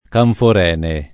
[ kanfor $ ne ]